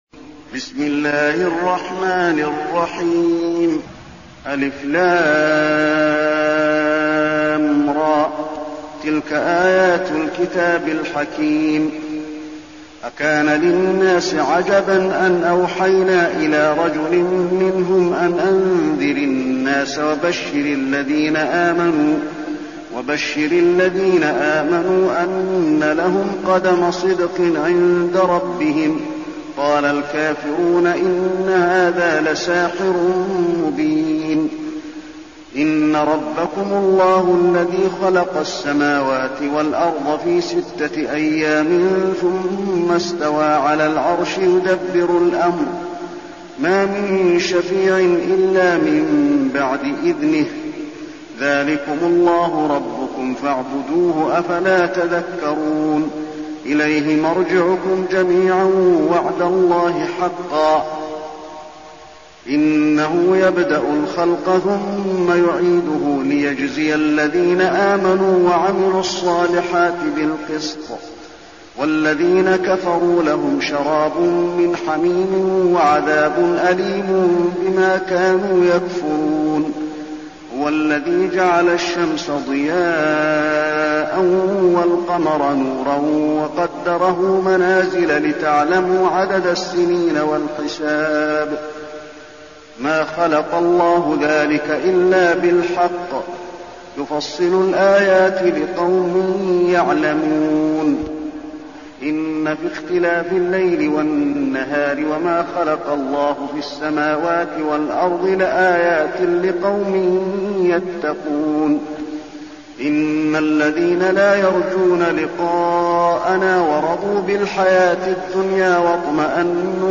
المكان: المسجد النبوي يونس